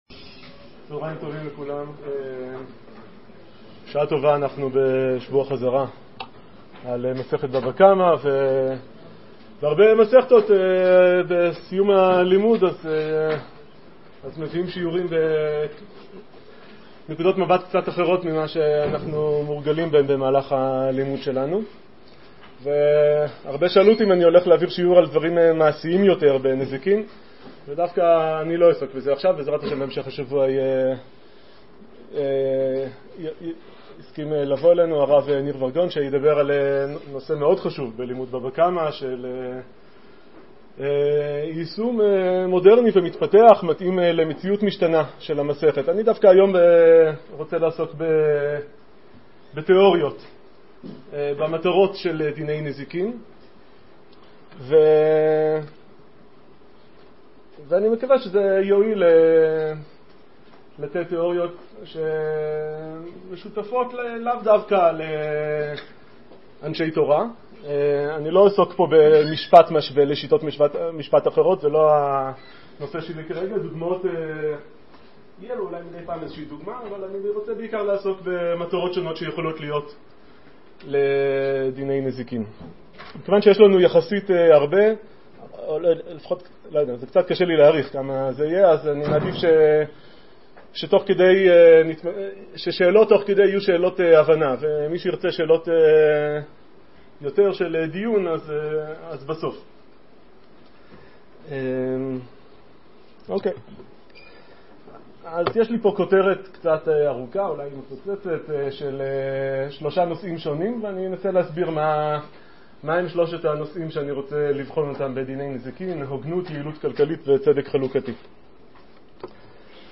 שיעור מיוחד לסוף זמן חורף תשע"ח.